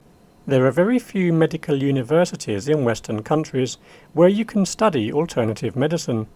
DICTATION 5